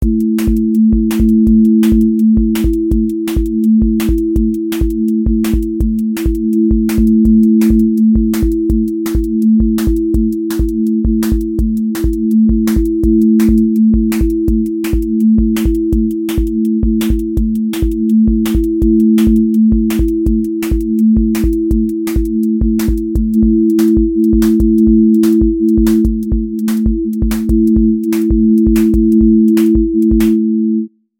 Drum-and-bass worker specimen with amen-led break pressure, switchups, sub ownership, and rolling atmosphere
• voice_kick_808
• voice_snare_boom_bap
• voice_hat_rimshot
• voice_sub_pulse
• fx_space_haze_light